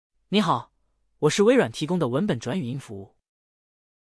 Edge-TTS是微软提供的在线文本转自然语音，支持多种语言和声音，转换速度快，语音自然无机械感。
同一段文字分别使用这几种声音转成语音文件对比：